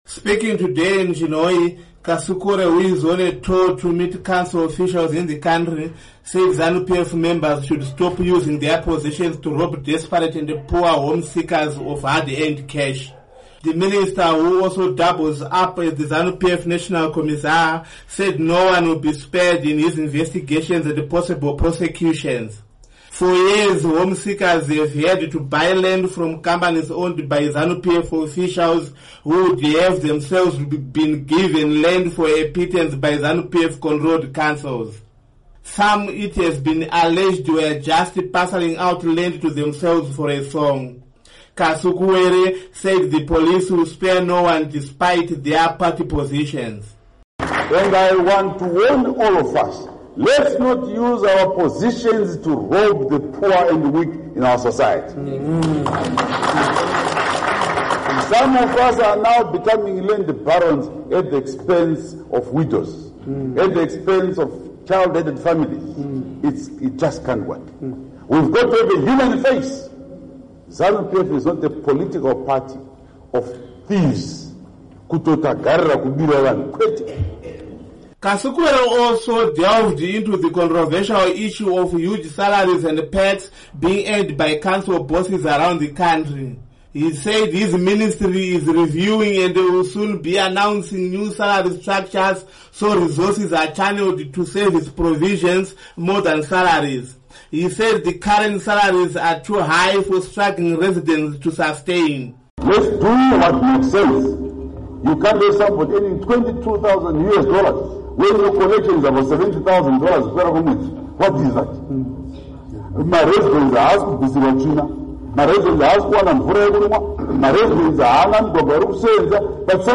Speaking on the sidelines of his tour of Chinhoyi town in Mashonaland West province, Kasukuwere said Zanu PF members should stop using their positions to rob desperate and poor home-seekers of their hard-earned cash.